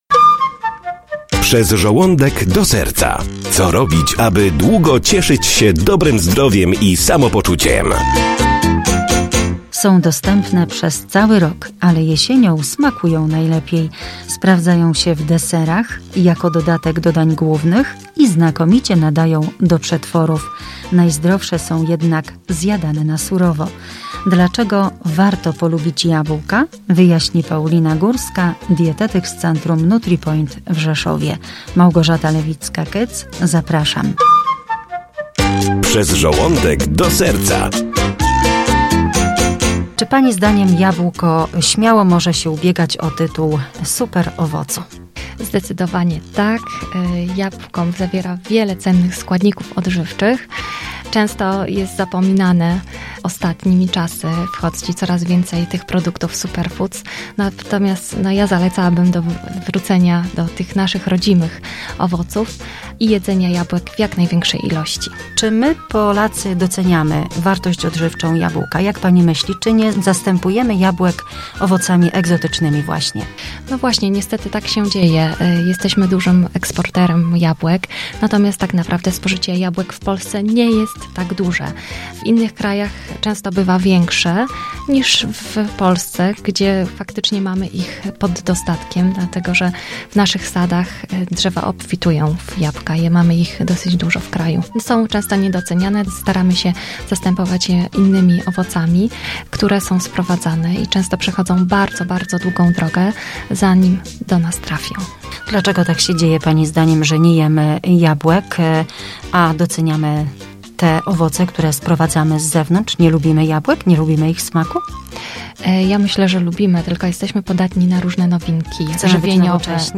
na antenie radia Via, na temat właściwości zdrowotnych jabłek.